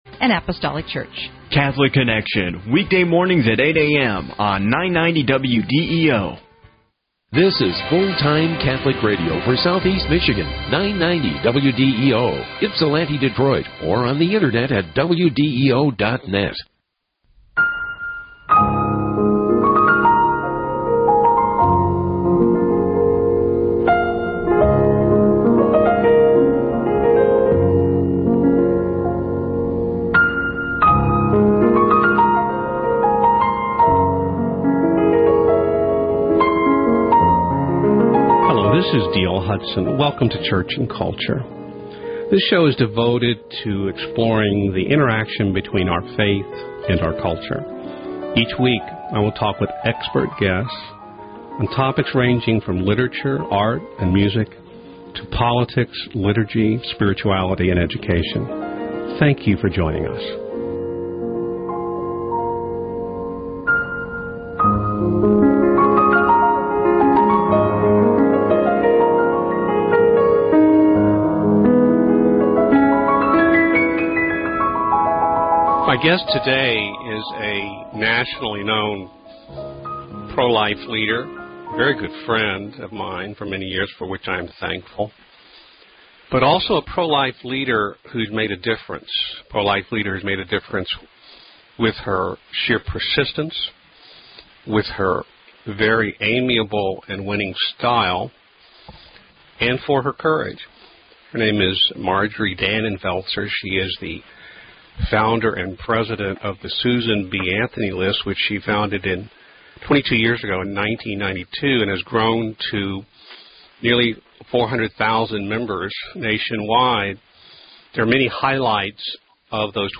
Marjorie Dannenfelser: president of the Susan B. Anthony List talks with me about the special challenges facing a pro-life organization that engages in politics.